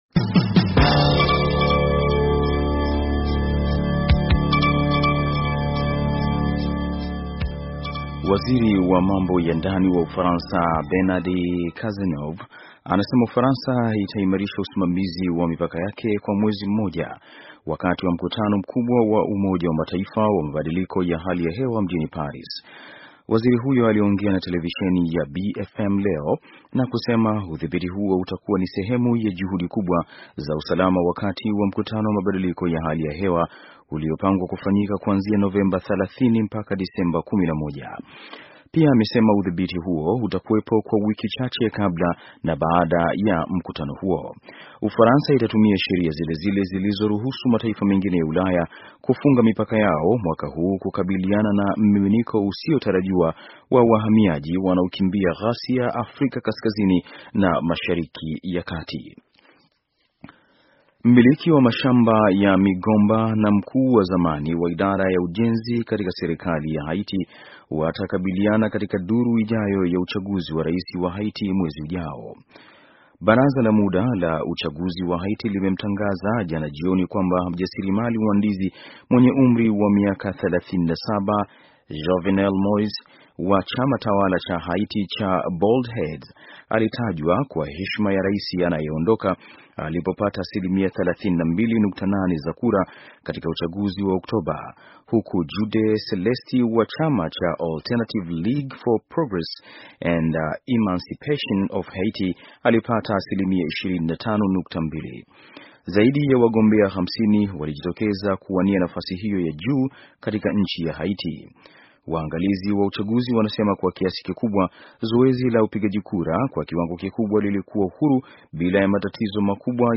Taarifa ya habari - 5:51